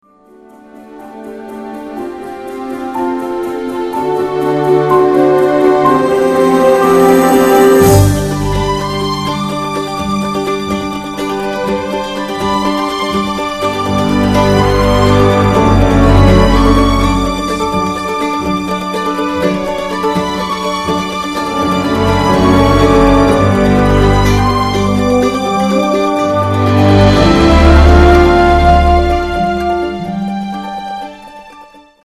violins (28), violas (12), cellos (12), double basses (8)
horns (4), percussion (5), harp